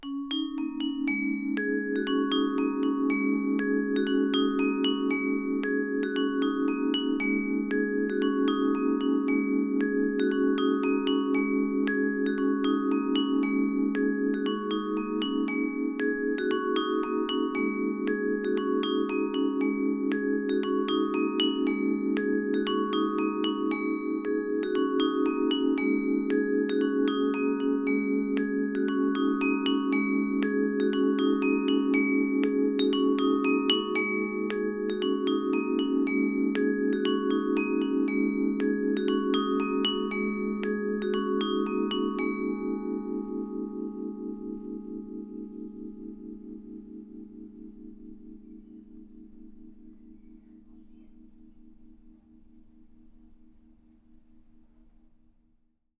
vibraphon.aiff